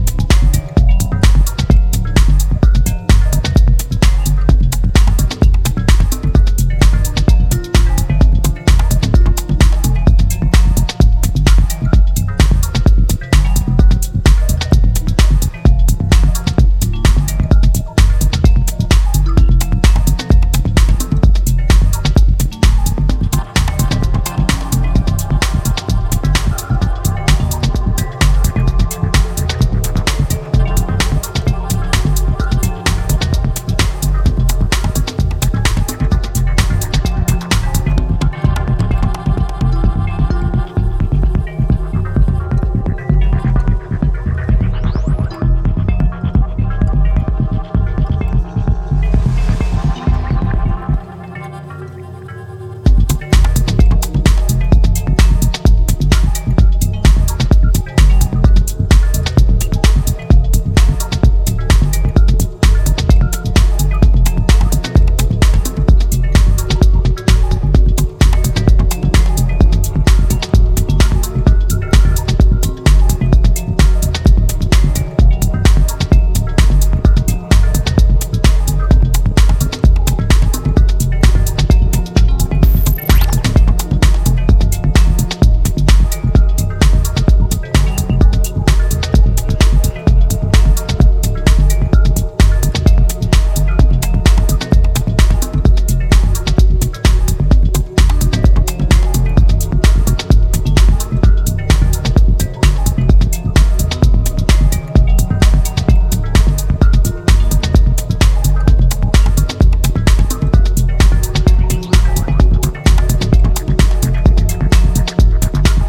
blend House grooves with minimalistic elements